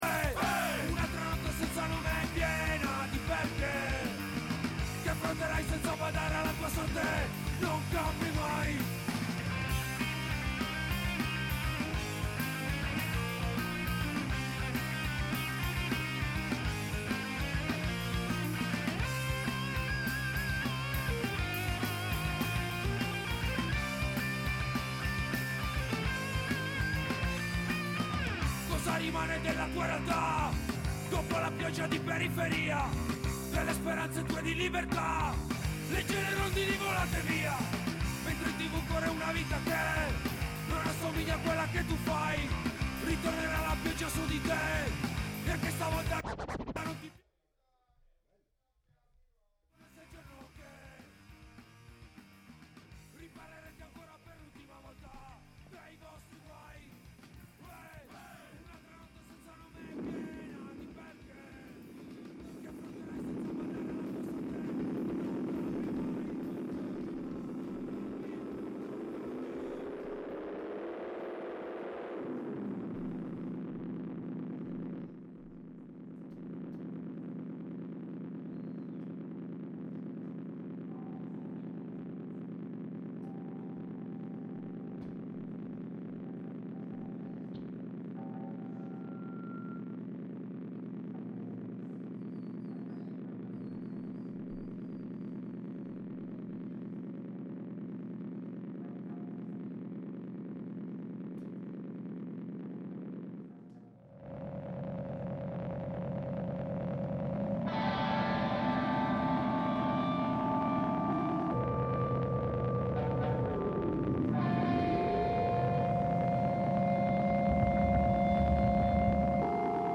dibattito) Presso il Teatro Verdi – Milano